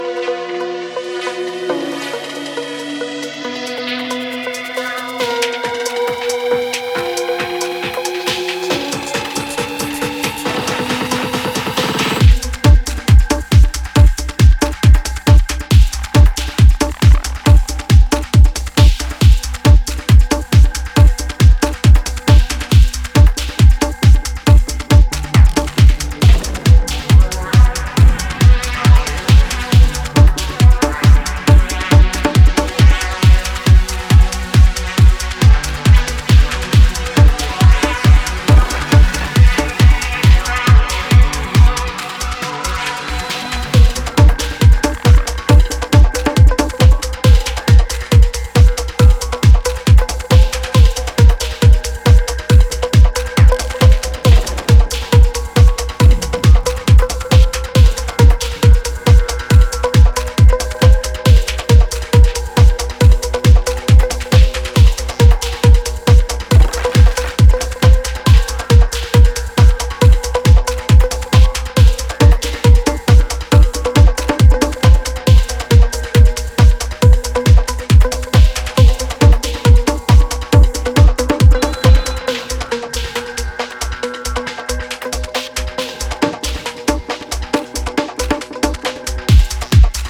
オーガニックなテクスチャーを軸に卓越したグルーヴ感とサイケデリックな音響志向が見事に合致したテクノの新感覚を提示。